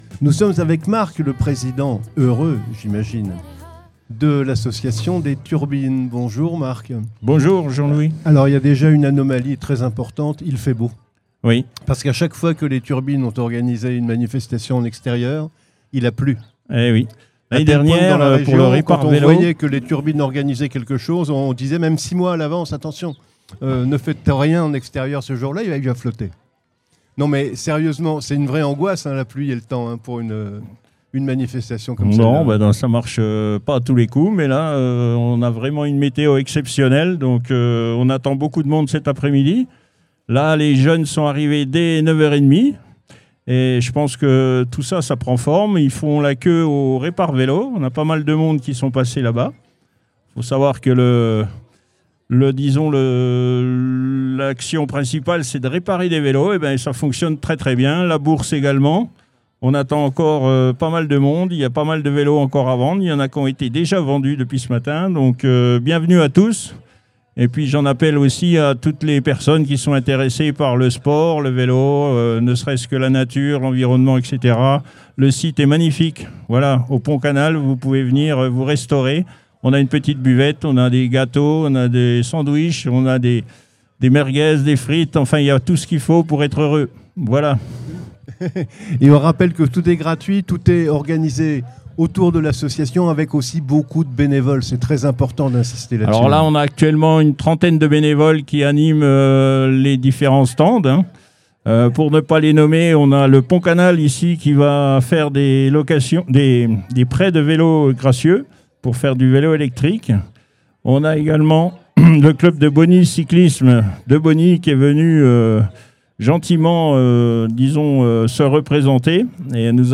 Interview
À l’occasion du Grand Répar Vélo des Turbines, Studio 45 vous propose une série d’interviews réalisées en direct du Pont Canal de Briare.
Entre rencontres humaines, initiatives locales et ambiance conviviale, plongez au cœur d’un événement engagé autour du vélo, du recyclage et du vivre-ensemble.